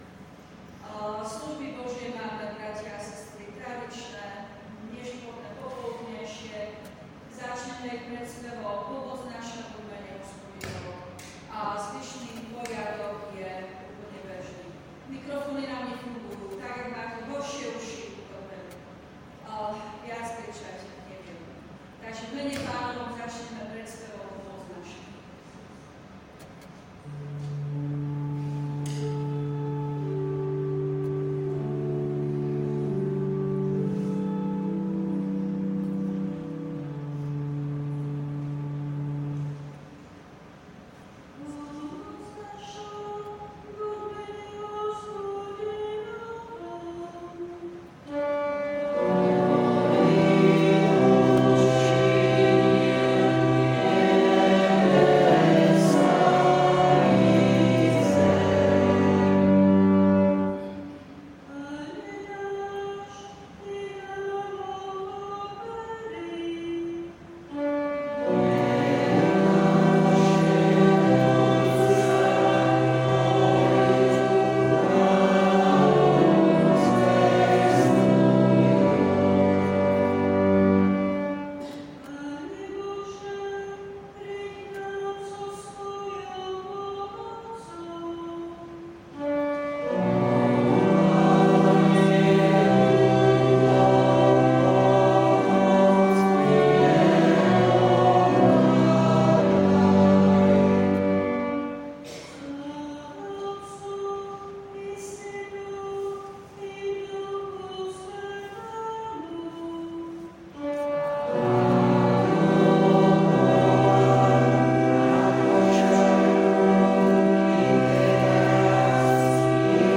V nasledovnom článku si môžete vypočuť zvukový záznam z nešporných služieb Božích – Nový rok.